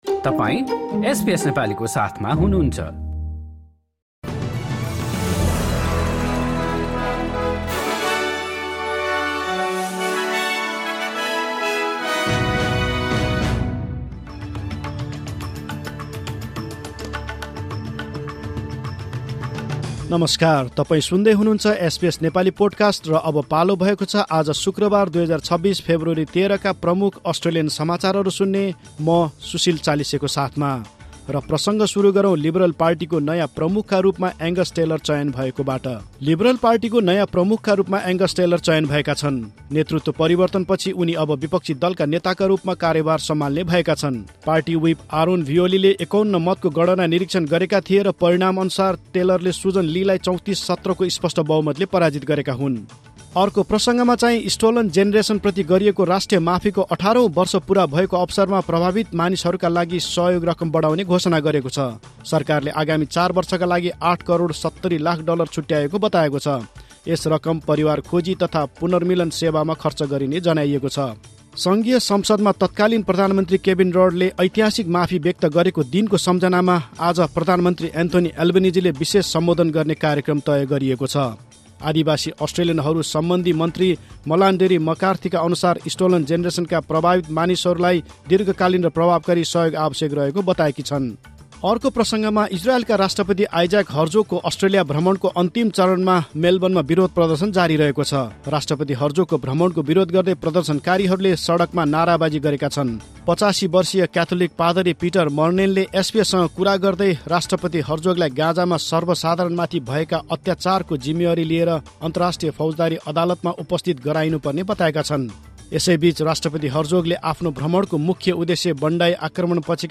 एसबीएस नेपाली प्रमुख अस्ट्रेलियन समाचार: शु्क्रवार, १३ फेब्रुअरी २०२६